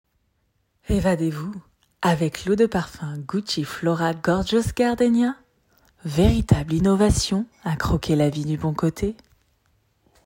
publicité parfum